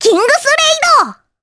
Rephy-Vox_Kingsraid_jp.wav